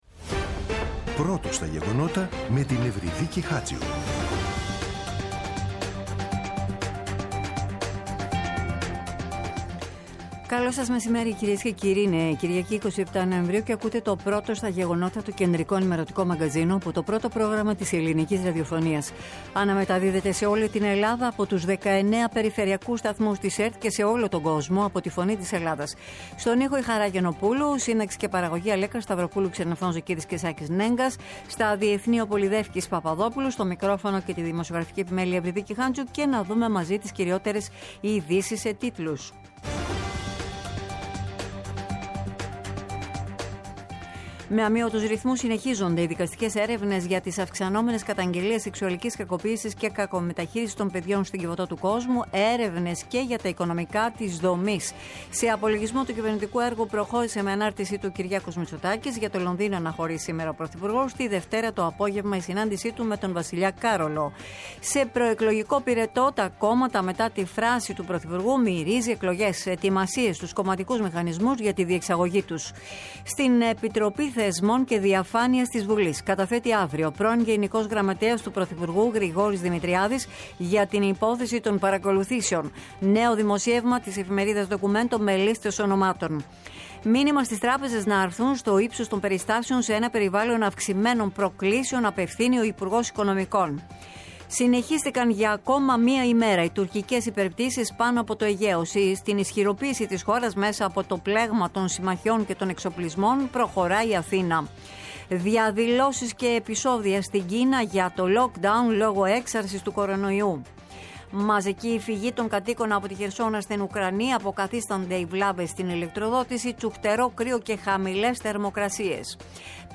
“Πρώτο στα γεγονότα”. Το κεντρικό ενημερωτικό μαγκαζίνο του Α΄ Προγράμματος στις 14.00. Με το μεγαλύτερο δίκτυο ανταποκριτών σε όλη τη χώρα, αναλυτικά ρεπορτάζ και συνεντεύξεις επικαιρότητας.